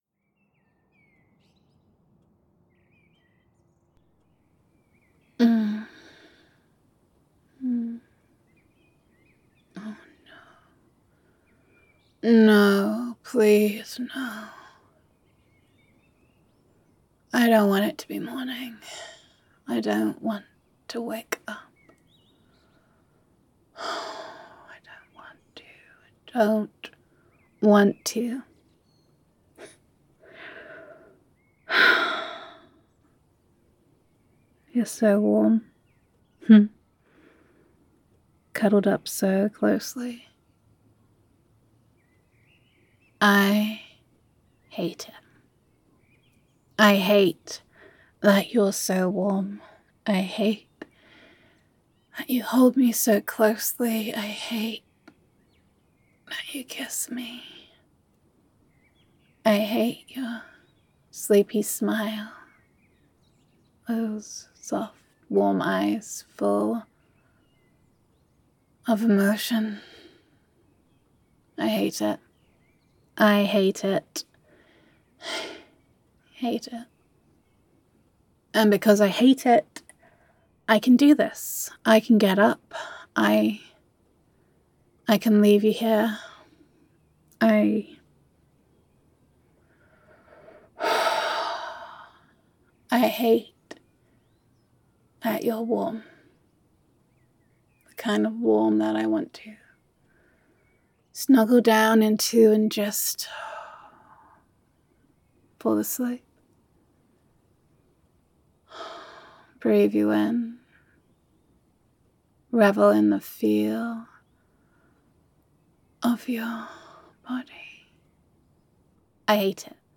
[F4A] Five More Minutes [Cuddles][Sleepy Voiced][I Don’t Want to Get up][I Hate How Good Your ARMS Feel Around Me][Adoration][Gender Neutral][Your Girlfriend Hates Your Cuddles]